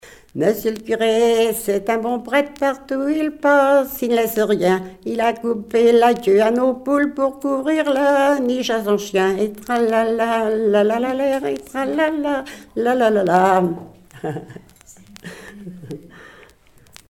branle
Couplets à danser
gestuel : danse
Pièce musicale éditée